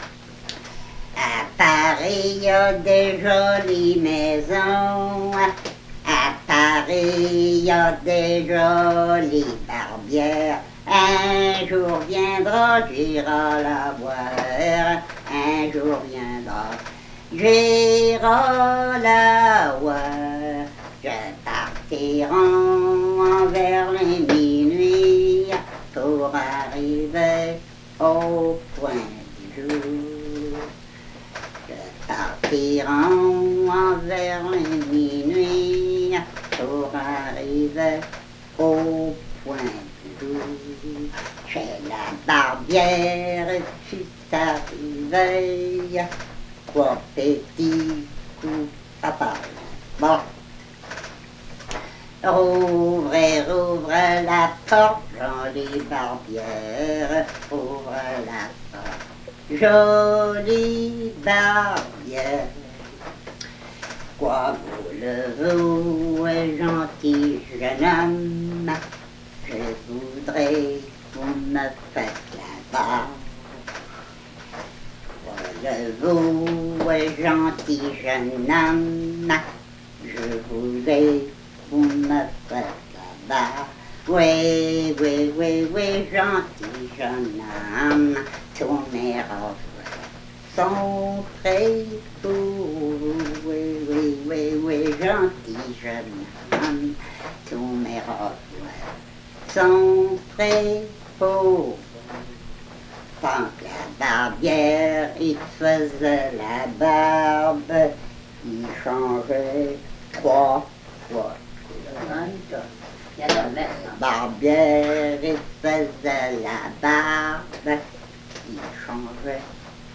Chanson
Emplacement Cap St-Georges